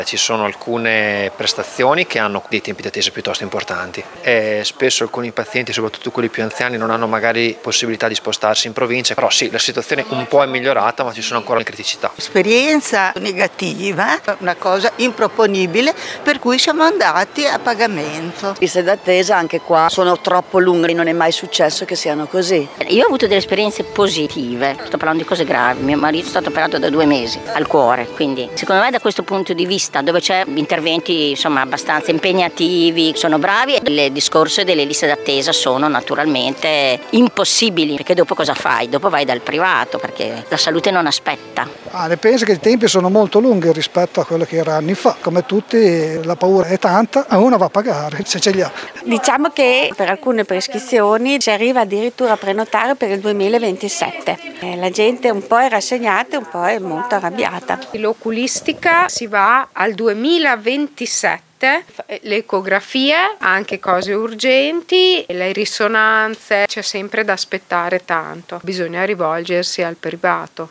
Sempre più cittadini costretti a ricorrere al privato, le interviste: